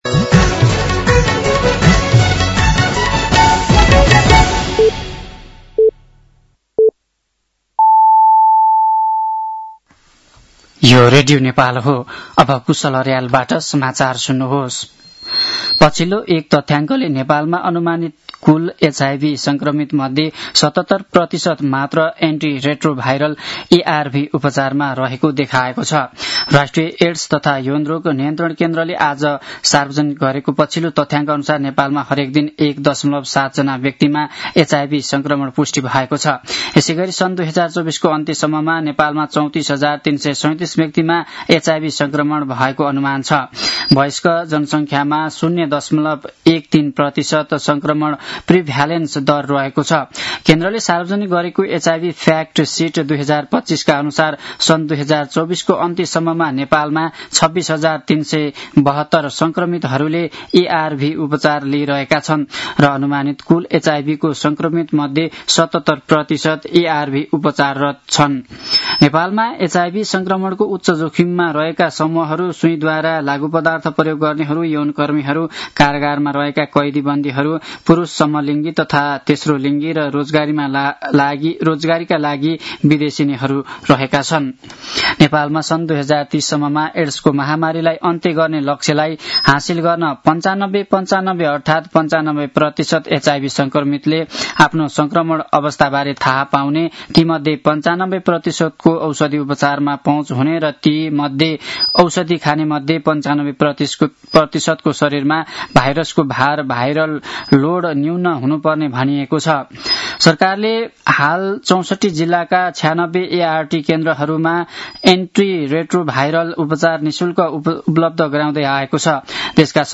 साँझ ५ बजेको नेपाली समाचार : १४ मंसिर , २०८२
5.-pm-nepali-news-1-3.mp3